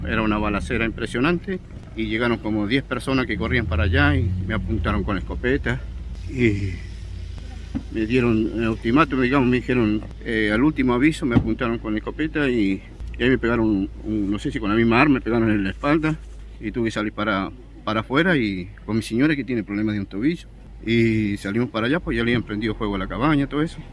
Los testimonios recogidos en el lugar por Radio Bío Bío son desoladores y dan cuenta de la acción coordinada, violenta e impune de hombres fuertemente armados.